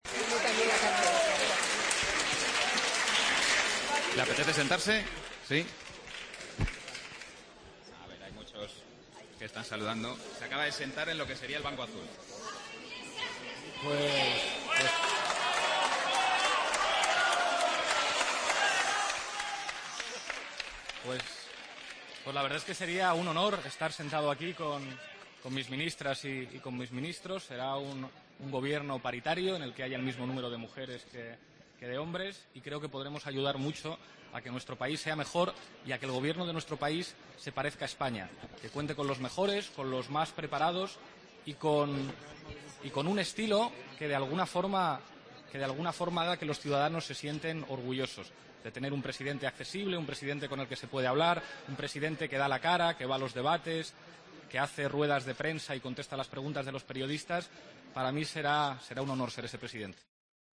AUDIO: Abucheos y aplausos a Pablo Iglesias en su "toma de posesión" simbólica del escaño de Presidente